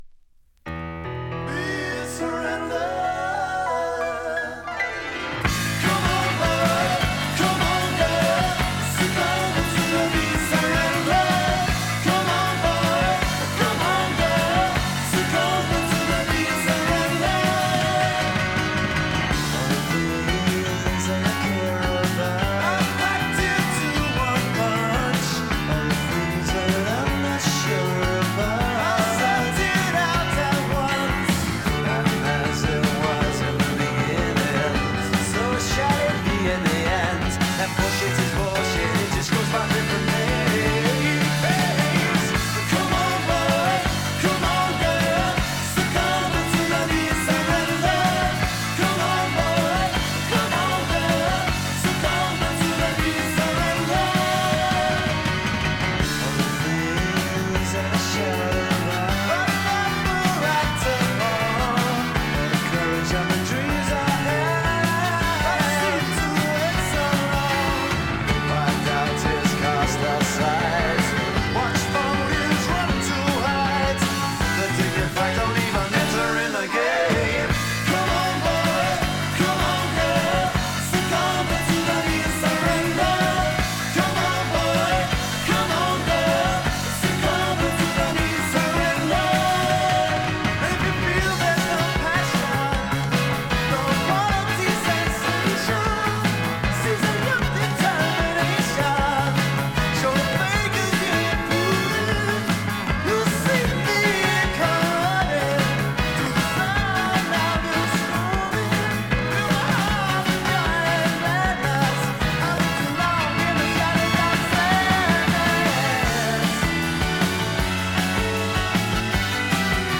現物の試聴（両面すべて録音時間１７分９秒）できます。